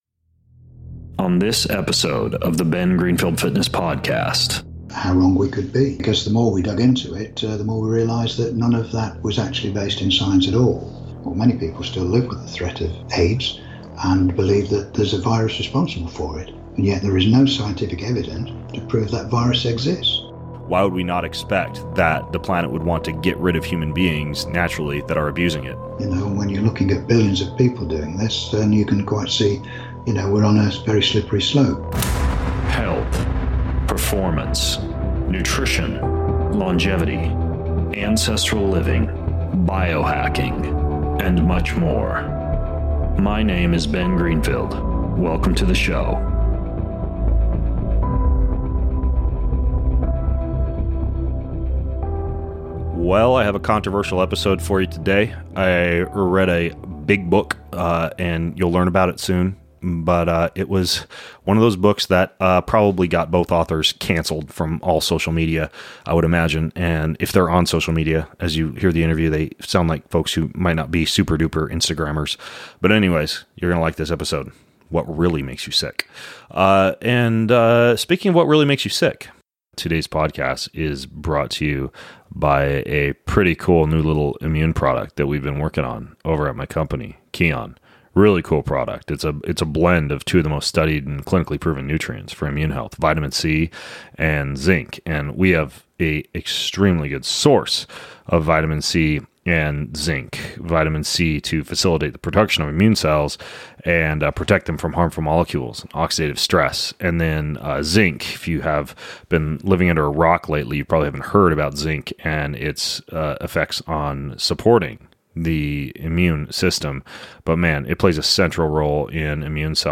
Interview on the Ben Greenfield Podcast - What Really Makes You Ill